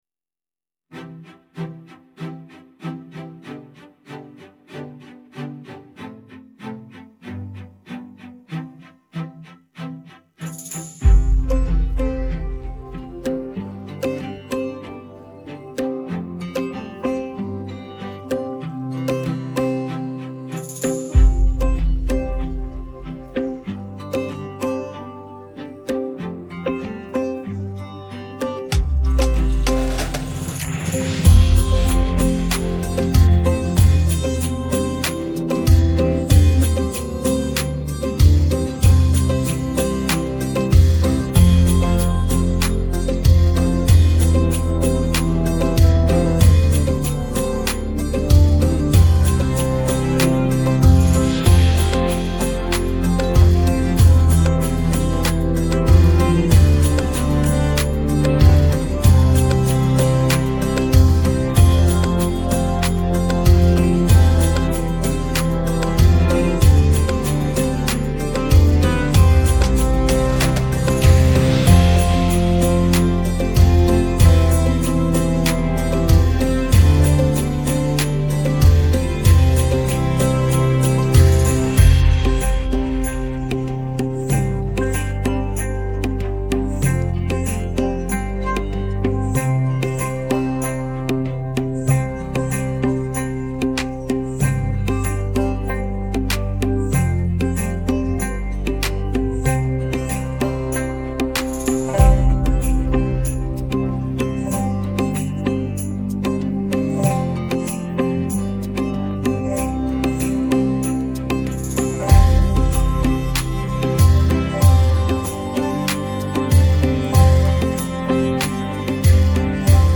Фоновая музыка